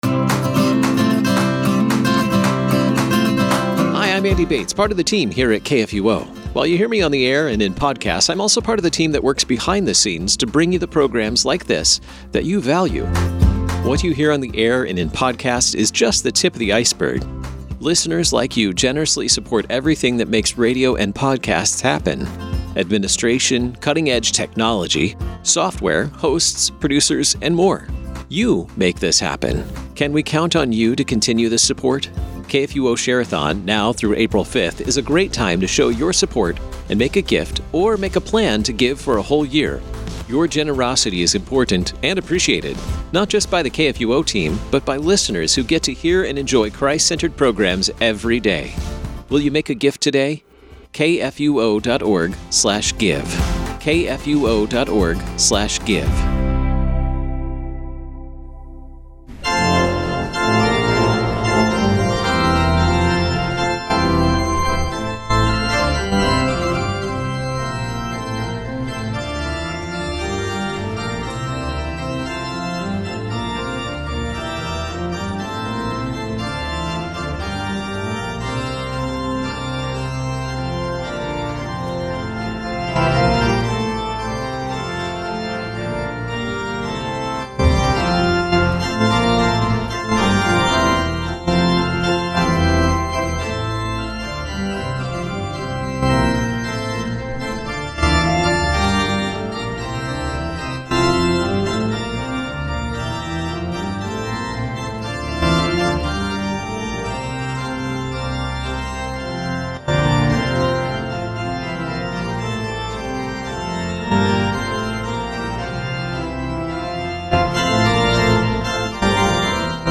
Hear the Bible Study from St. Paul's Lutheran Church in Des Peres, MO, from March 2, 2025.